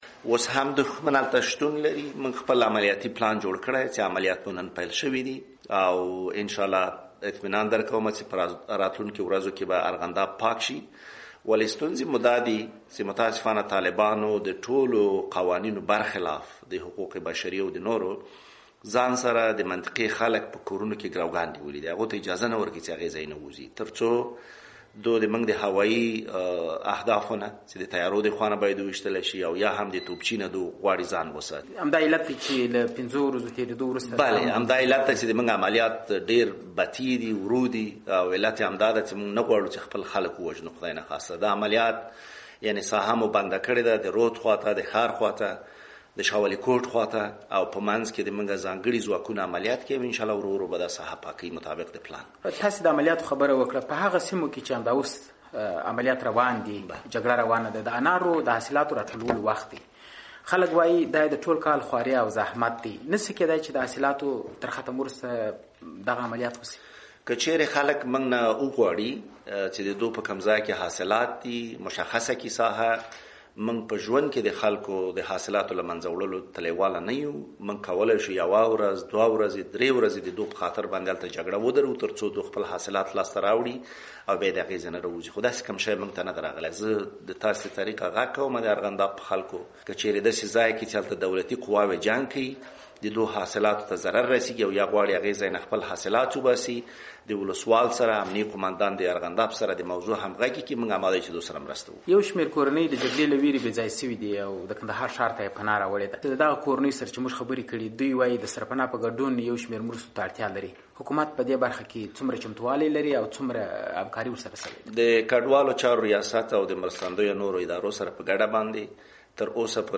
مرکه
له حیات‌الله حیات سره مرکه